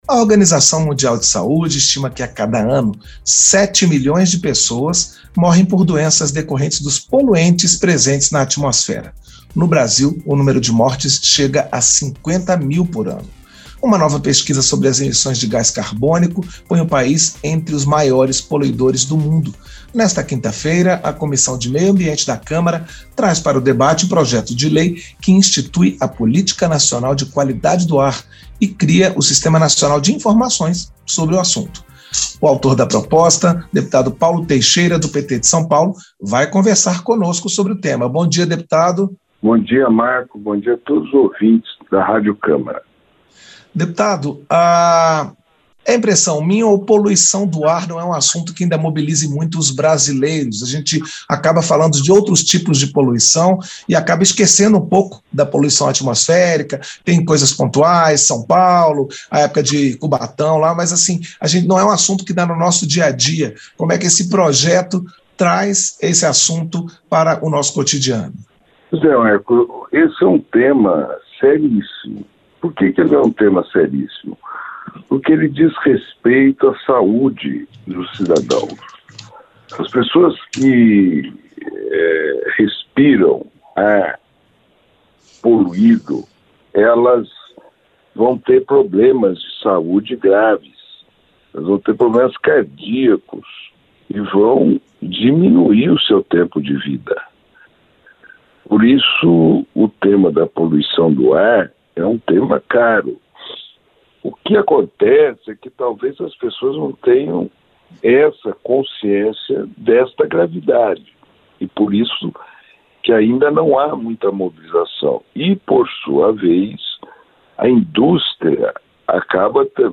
Entrevista - Dep. Paulo Teixeira (PT-SP)